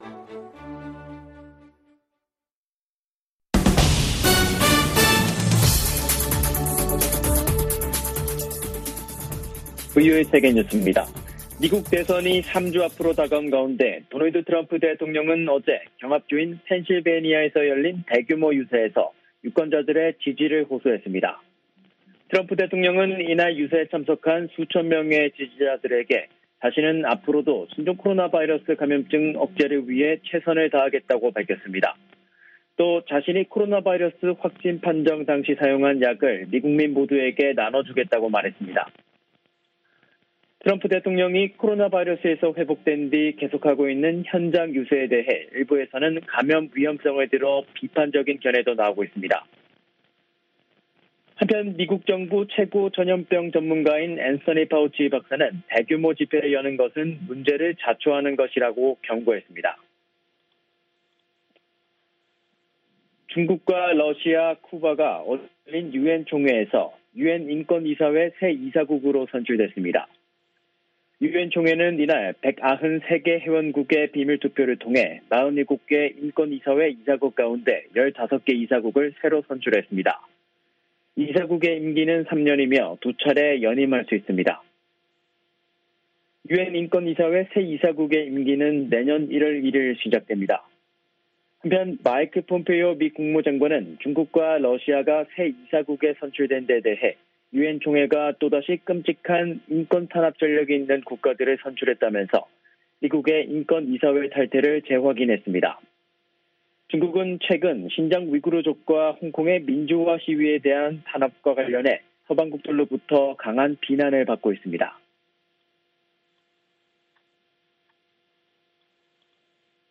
생방송 여기는 워싱턴입니다 저녁
세계 뉴스와 함께 미국의 모든 것을 소개하는 '생방송 여기는 워싱턴입니다', 저녁 방송입니다.